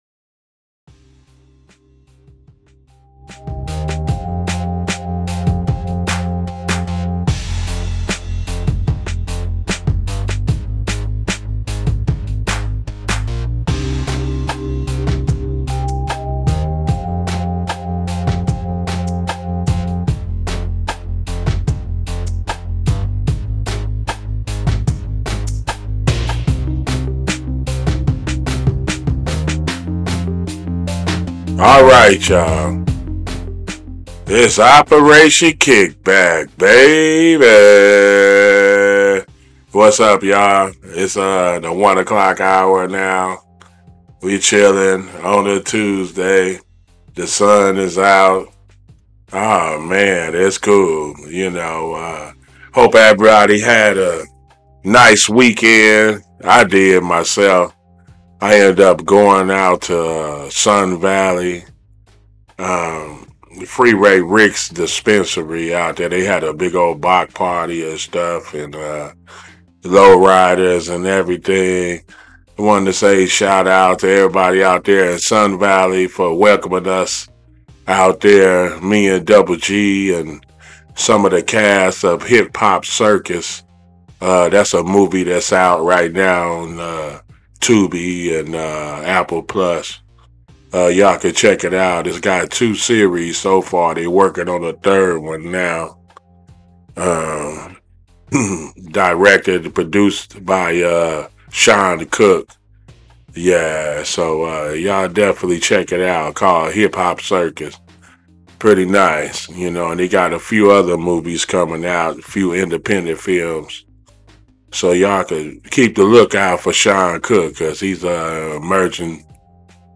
This episode of Operation KickBack aired live on CityHeART Radio on Tuesday May 21 at 1pm.